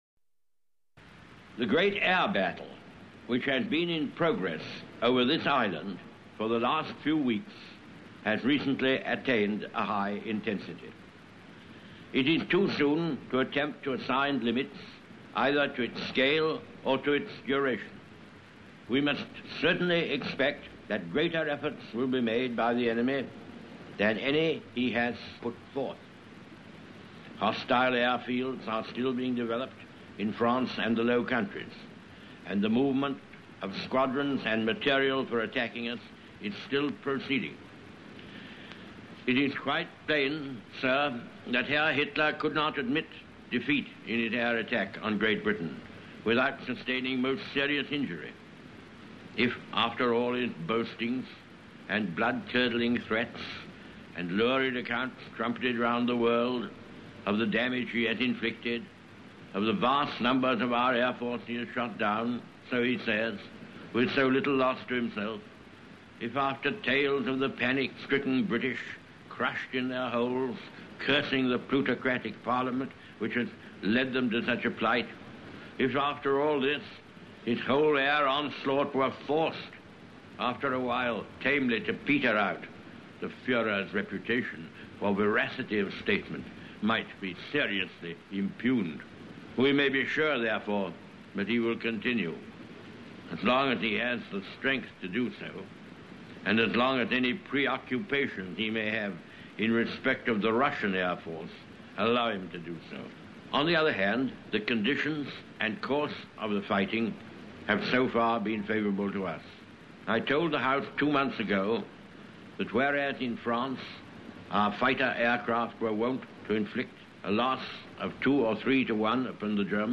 The Famous Wartime Speeches of Winston Churchill
This speech to Parliament, which was afterwards broadcast, was made at the height of the Battle of Britain, the result of which is still hanging in the balance. Churchill gives this stirring tribute to the pilots fighting the battle.